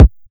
Carousel (Kick).wav